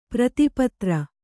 ♪ prati patra